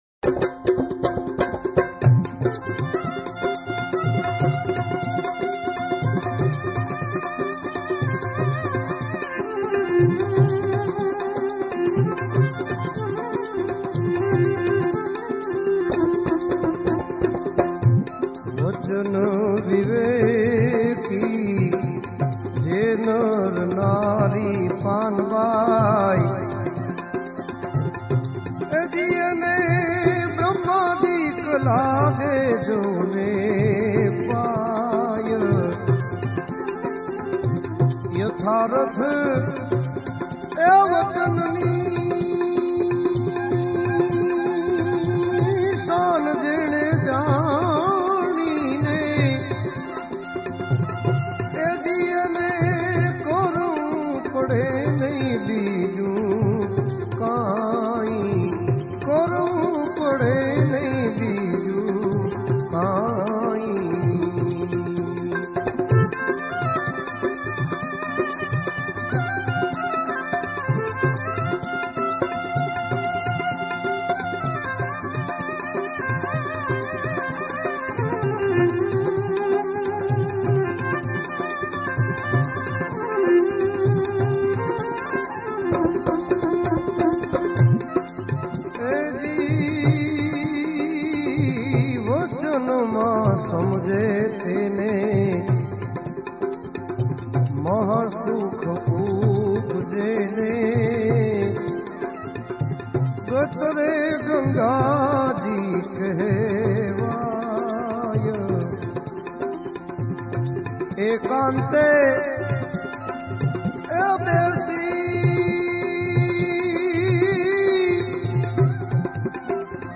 Gujarati Bhajan